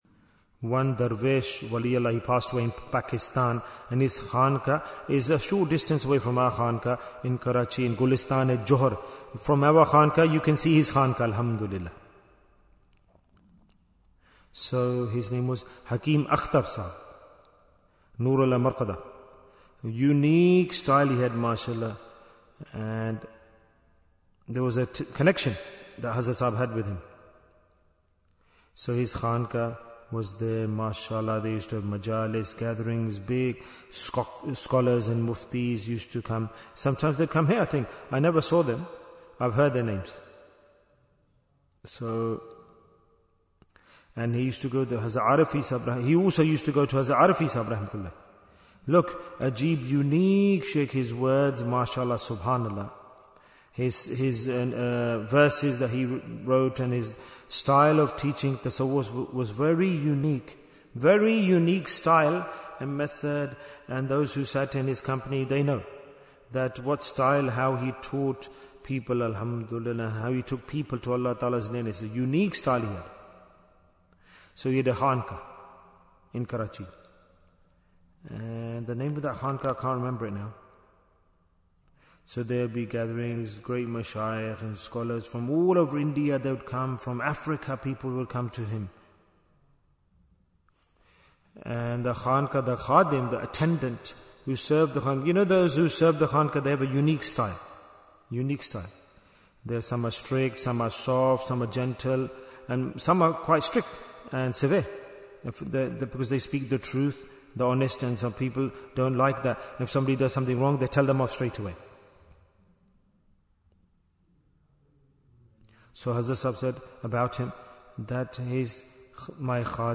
- Part 9 Bayan, 37 minutes 21st January, 2026 Click for Urdu Download Audio Comments Why Is Tazkiyyah Important? Part 9 A person can only be rectified by a Sheikh if he considers his inner self impure.